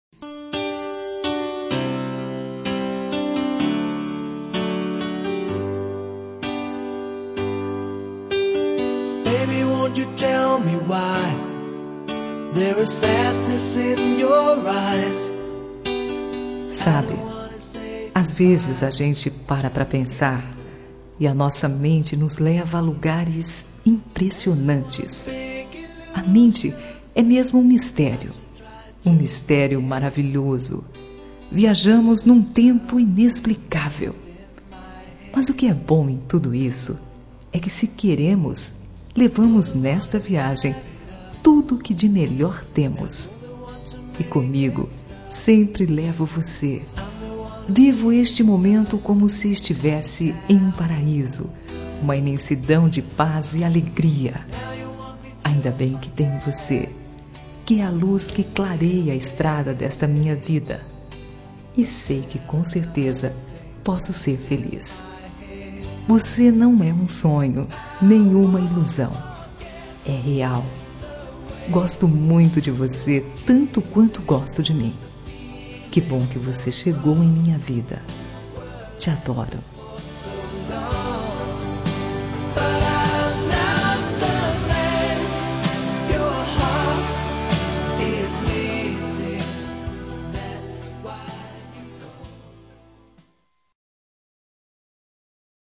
Telemensagem Início de Namoro – Voz Feminina – Cód: 750